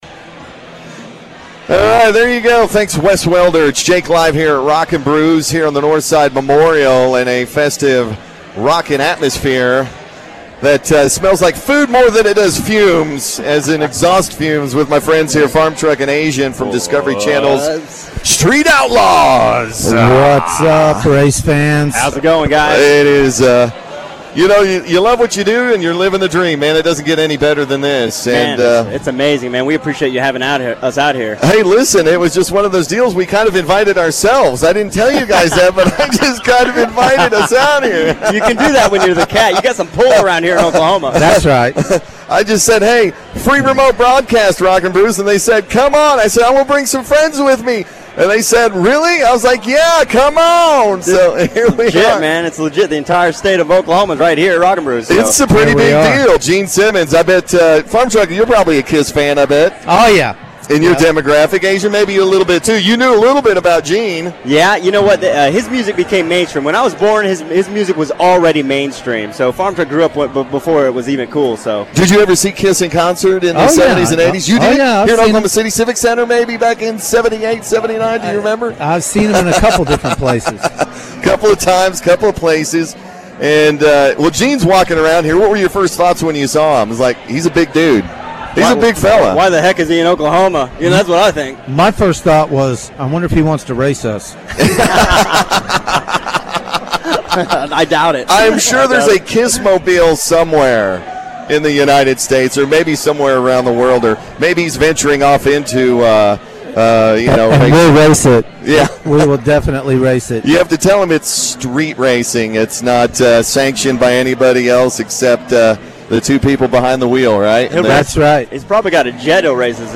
Gene Simmons meets Street Outlaws FarmTruck and Azn on the KATT